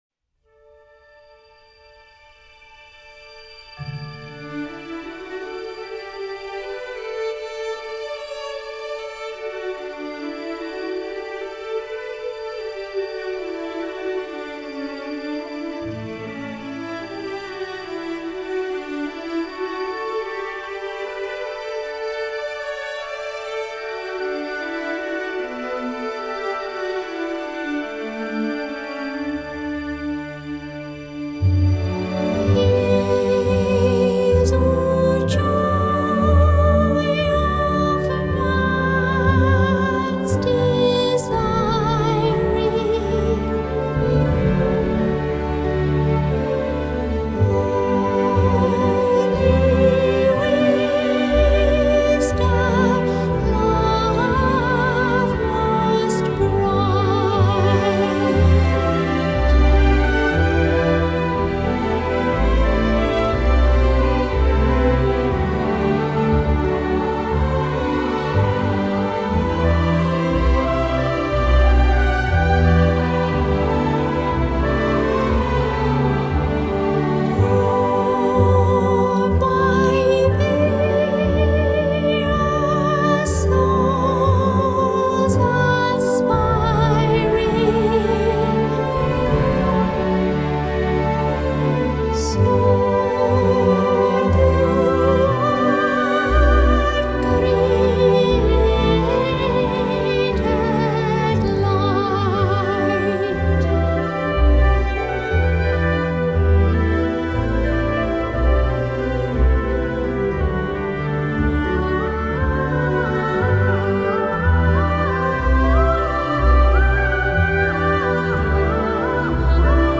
Choir
Orchestra
Organ [Church Organ]